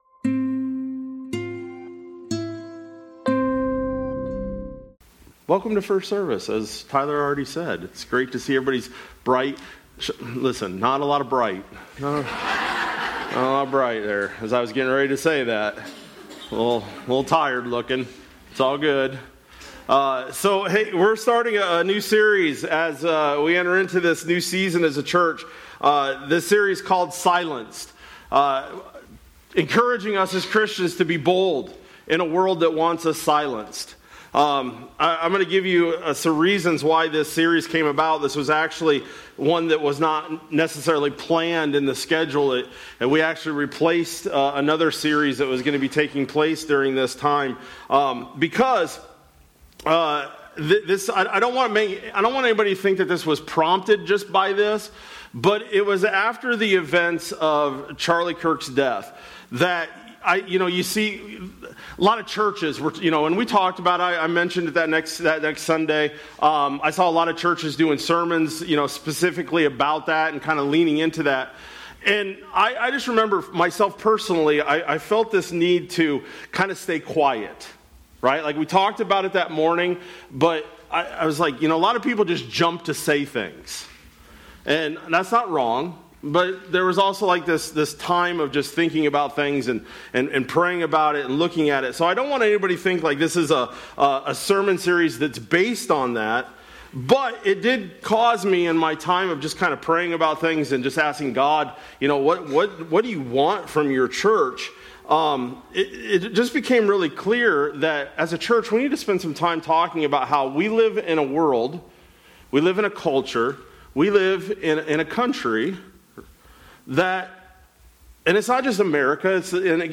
Nov-2-25-Sermon-Audio.mp3